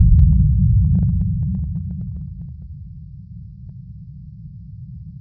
reheat-ignite.wav